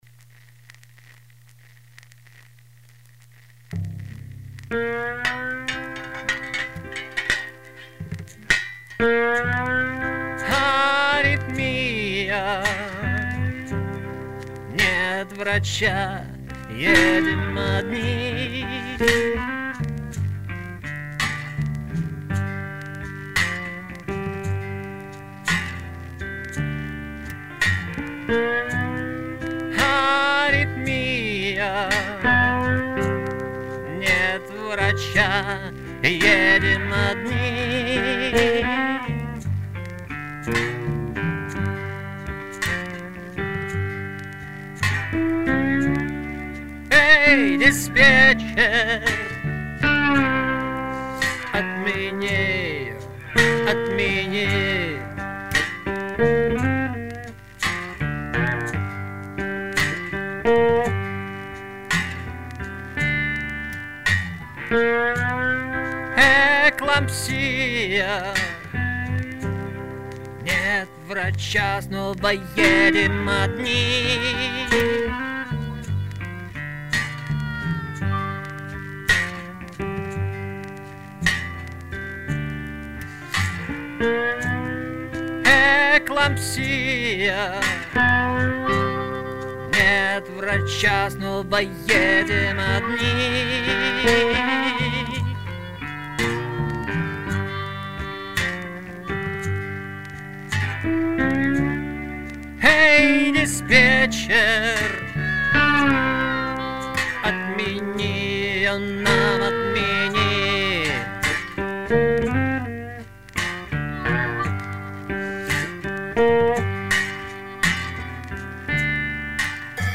песни скорой помощи
Грустный фельдшерский блюз.